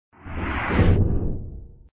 SWallMove.ogg